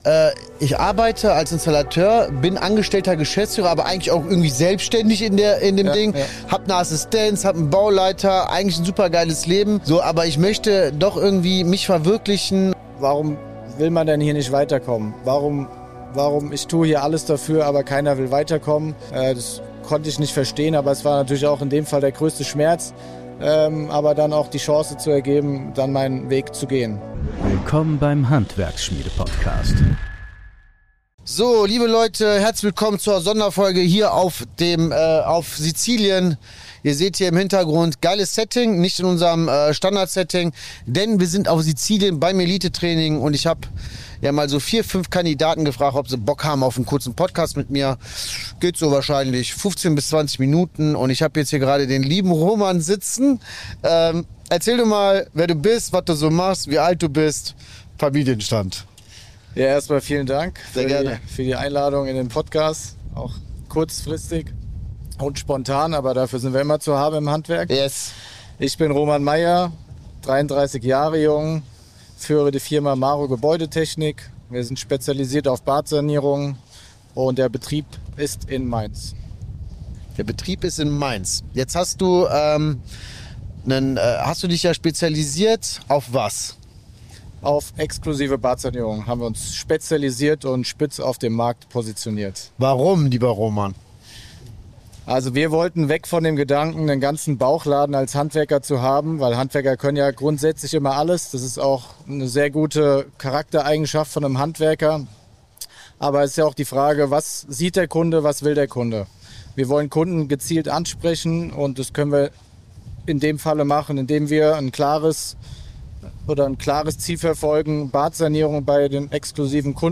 Vom sicheren Job als Angesteller zum eigenen Betrieb | Interview